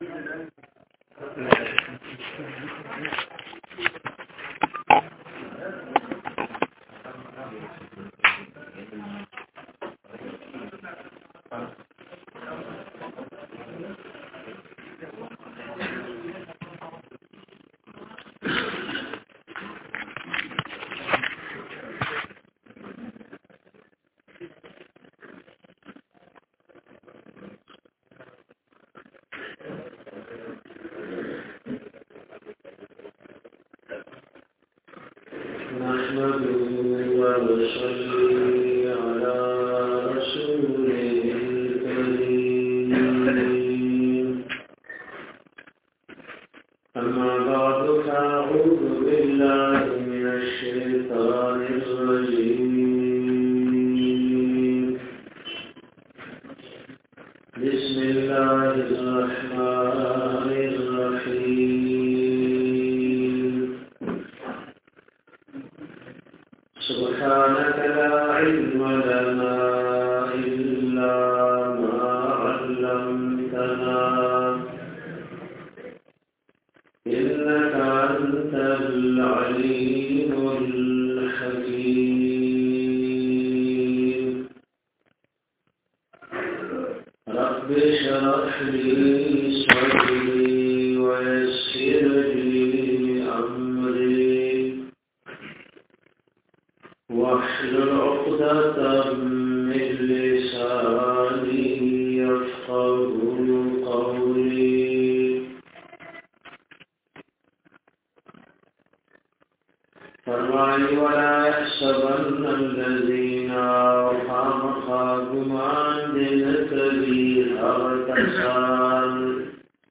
DARSE E QURAN